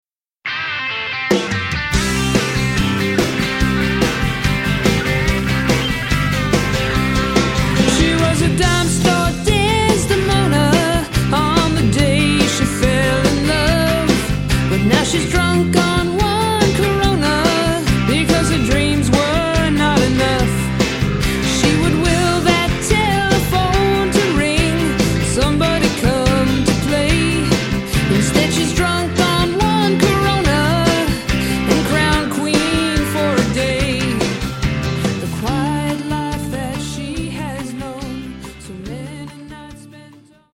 Sample from the Vocal MP3